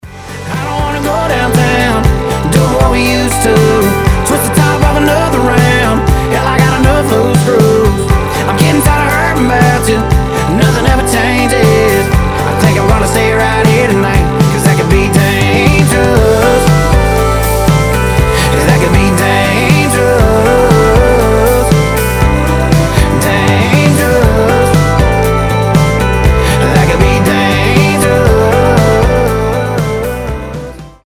• Качество: 320, Stereo
гитара
кантри